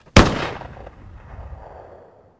sim_flintfire.wav